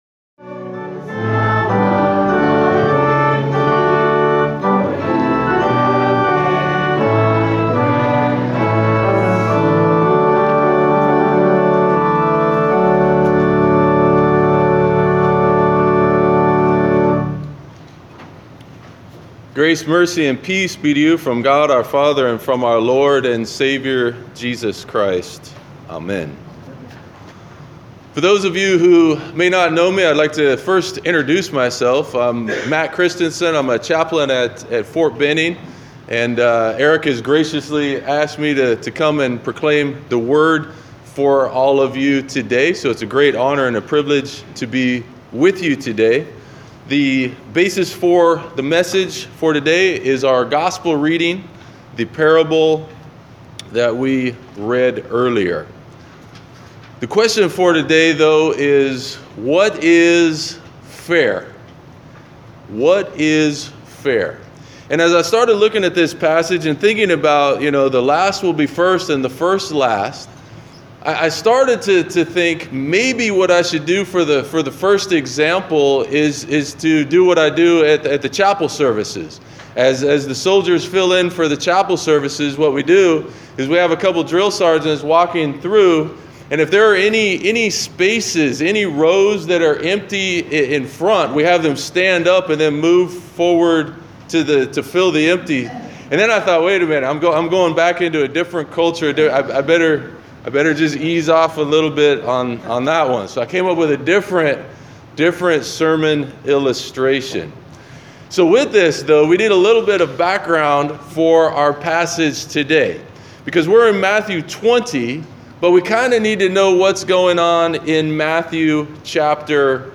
Sermon: Pentecost 16 Matthew 20:1-16